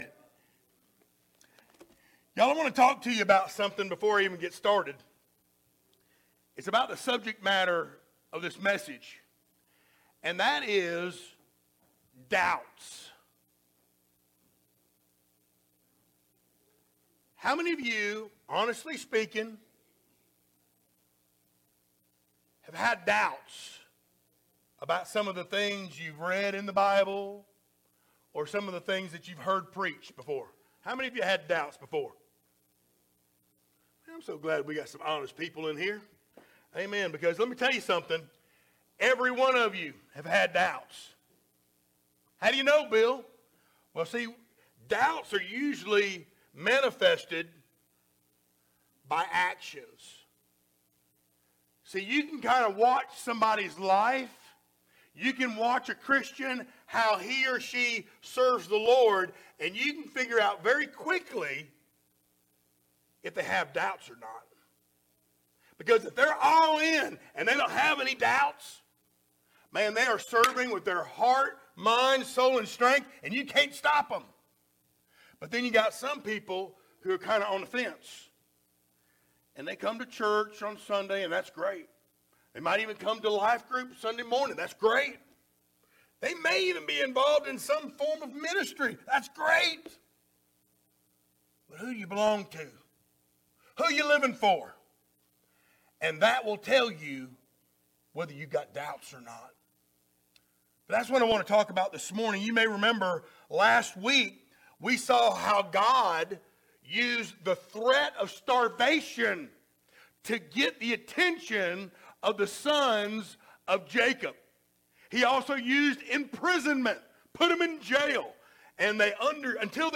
Series: sermons
Service Type: Sunday Morning